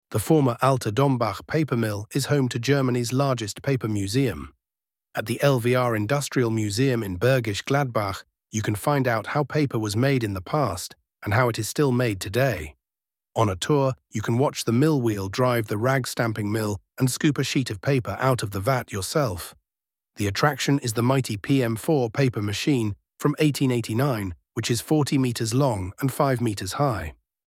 audioguide-paper-museum-english.mp3